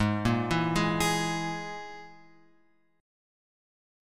G#sus2#5 chord {4 1 2 1 x 4} chord